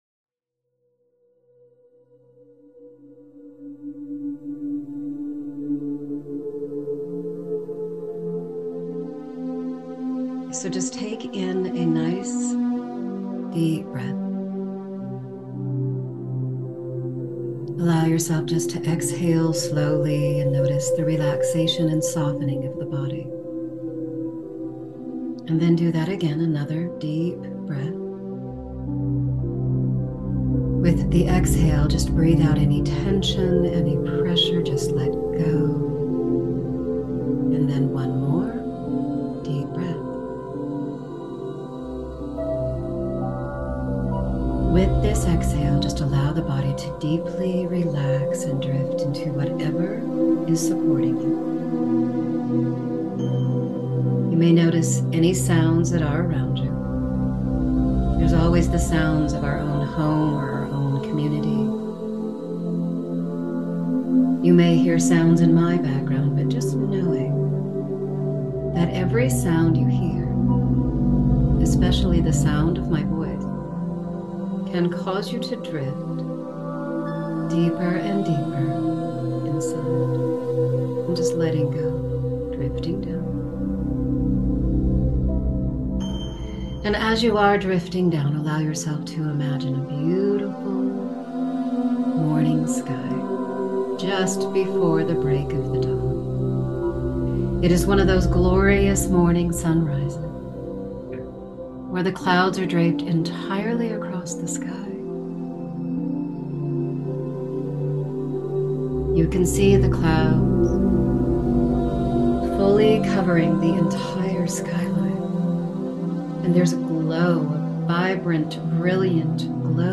A self-hypnosis meditation to align your chakras and tune into your Divine Guides, Angels, and Guardians. Guided Meditation in a soothing voice.